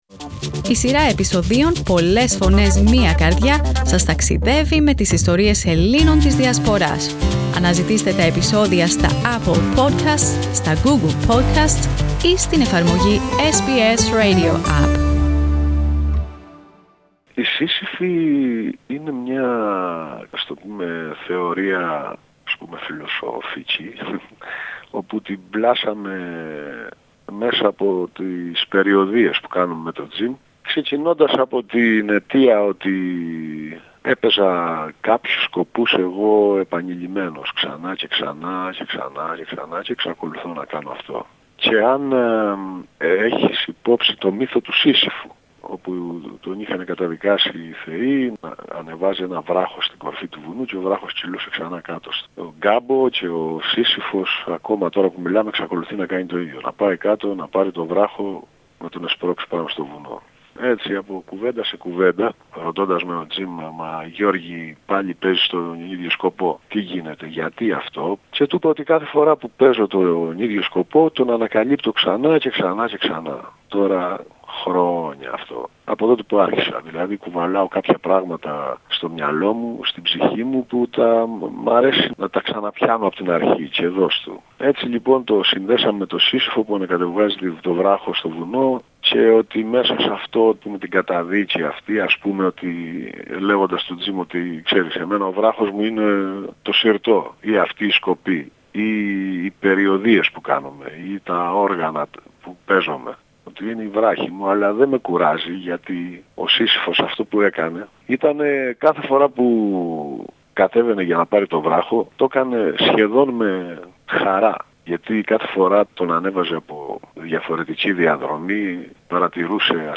Greek musician George Xylouris talks to SBS Greek about the new Album of the musical duo Xylouris White: ''The Sisypheans''. Drawing inspiration from the ancient myth of Sisyphus, Xylouris White rediscovers its musical identity.